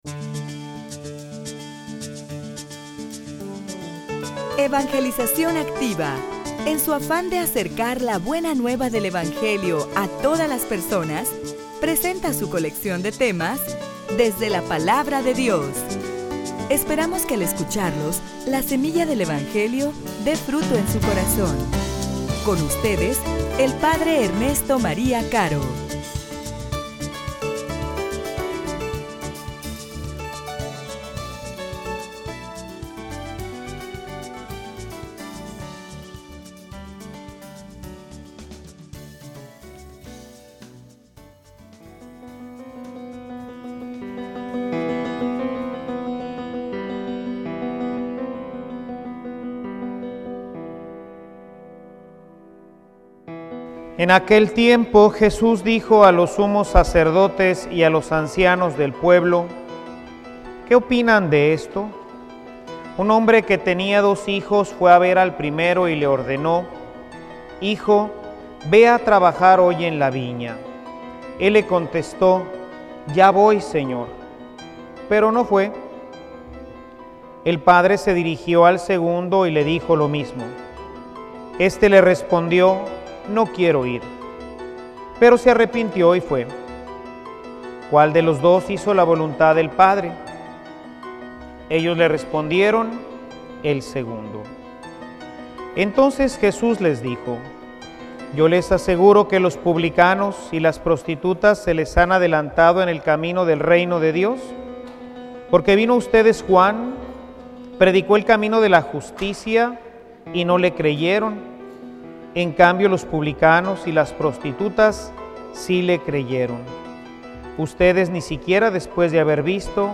homilia_La_obediencia_camino_seguro_a_la_felicidad.mp3